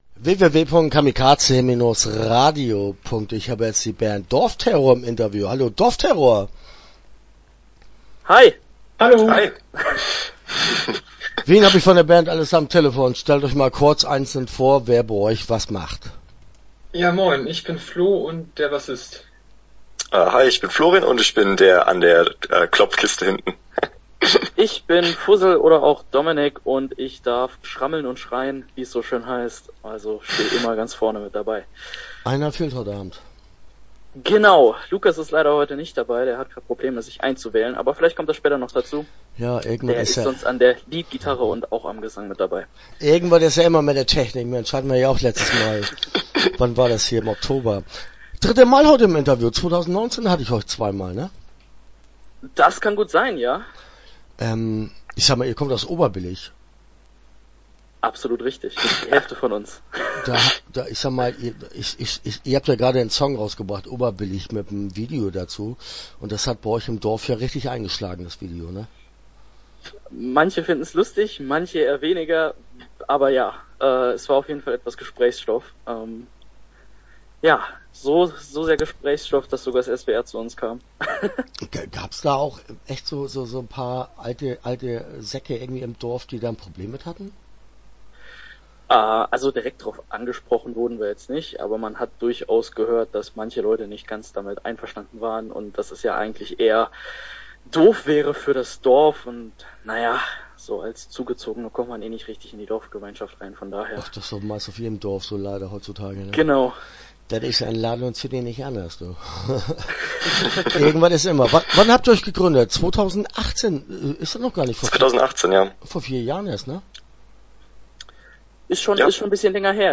Start » Interviews » Dorfterror